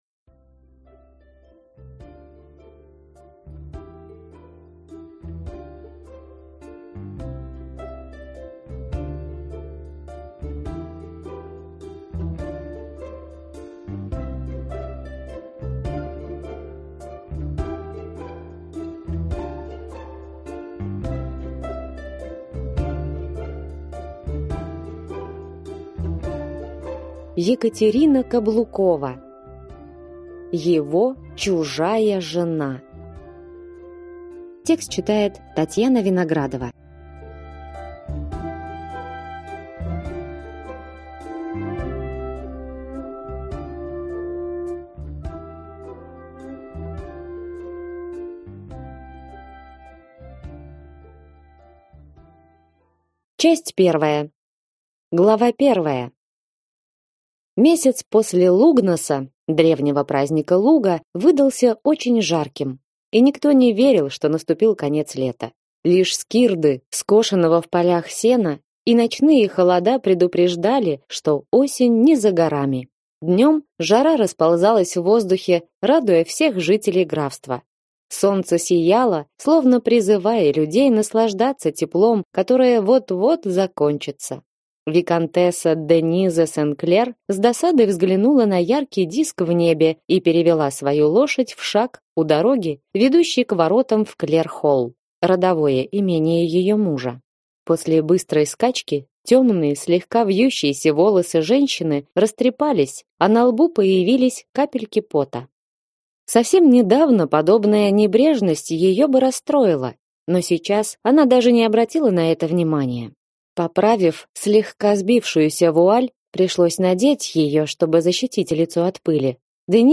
Аудиокнига Его чужая жена | Библиотека аудиокниг